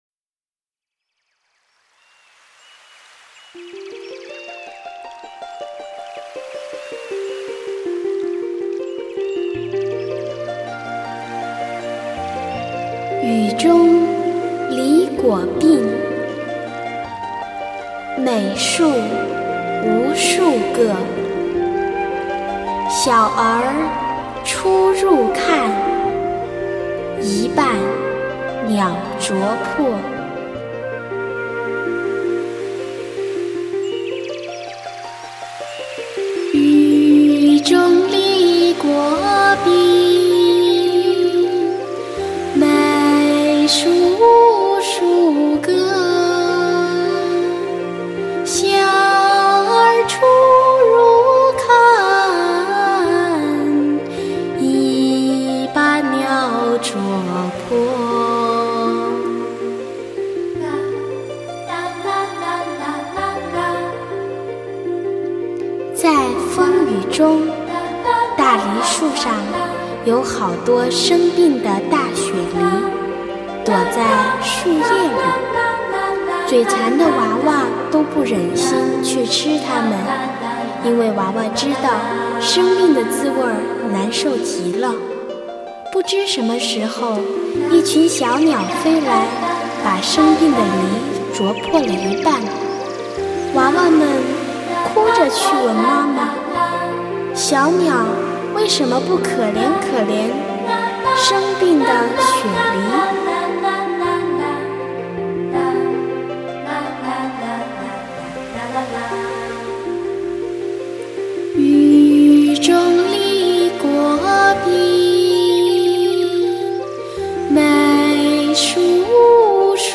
类型: 梵音佛语